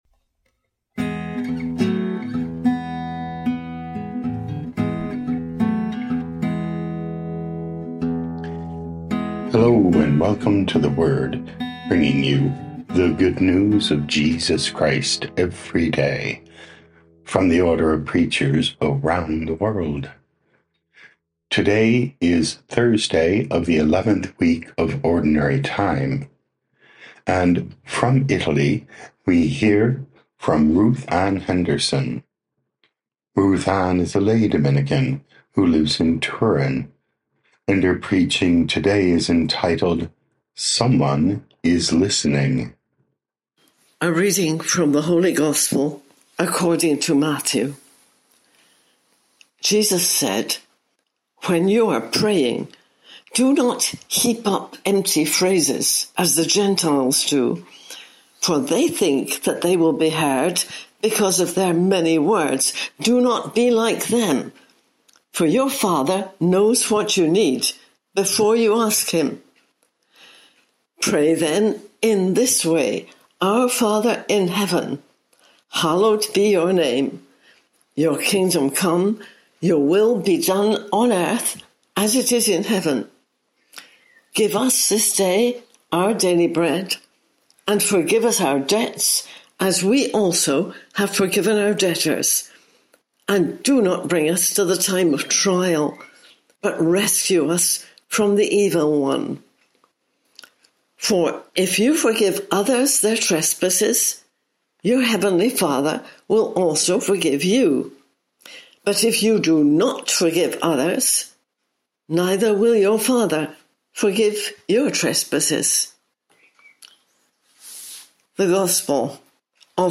19 Jun 2025 Someone is Listening Podcast: Play in new window | Download For 19 June 2025, Thursday of week 11 in Ordinary Time, based on Matthew 6:7-15, sent in from Turin, Italy.
Preaching